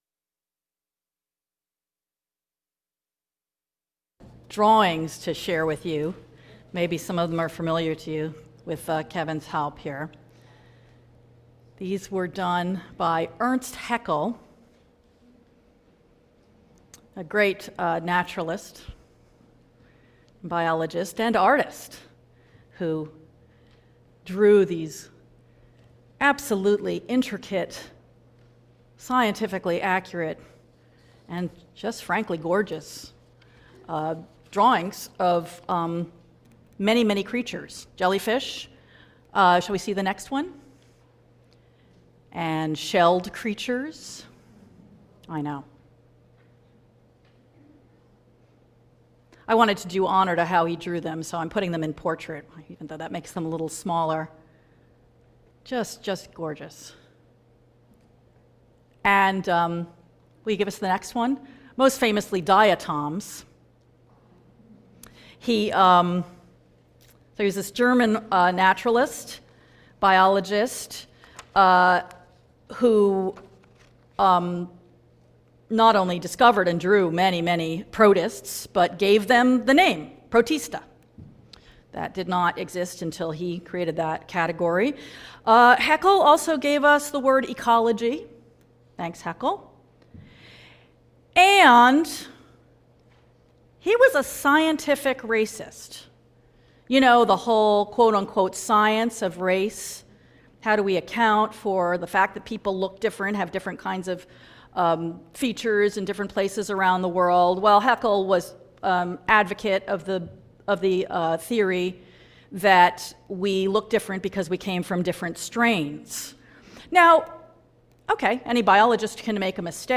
Sermons and Reflections